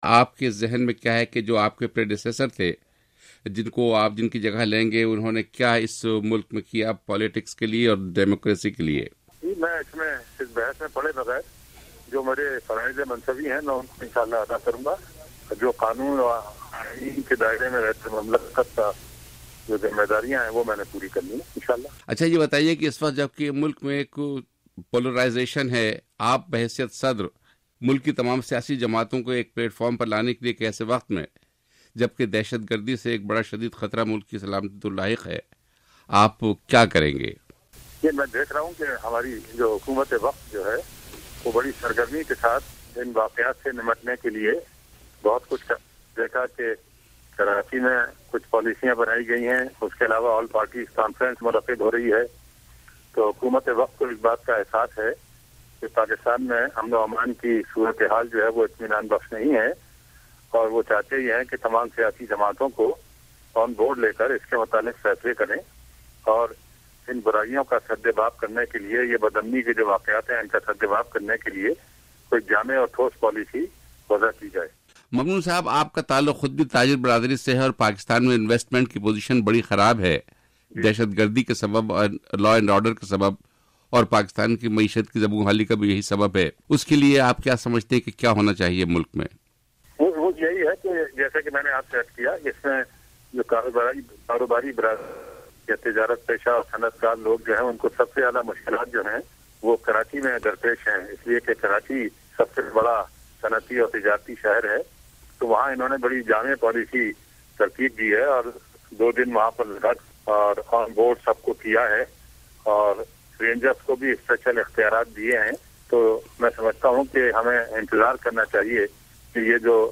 پاکستان کے نو منتخب صدر ممنون حسین سے وائس آف امریکہ کی خصوصی گفتگو